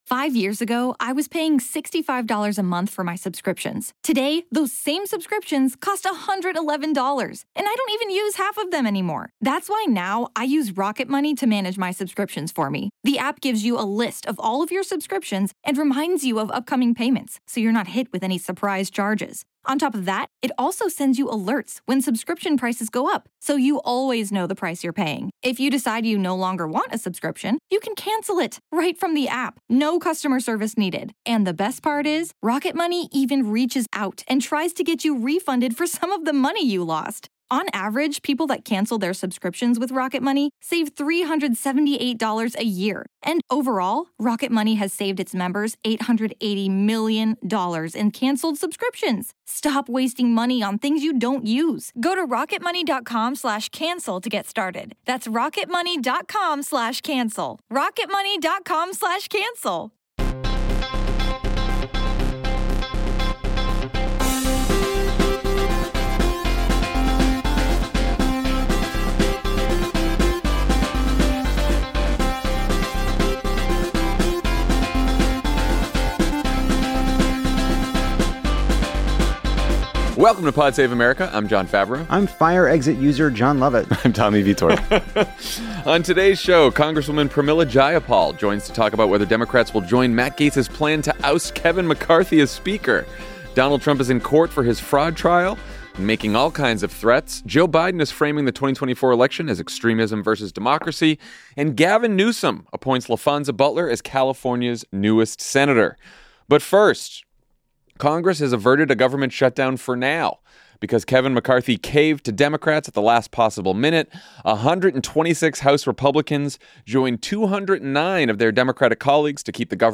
Later, Congressional Progressive Caucus Chair Pramila Jayapal joins the show to talk about House Democrats' strategy as the Speaker drama unfolds.